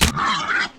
boar_hit.ogg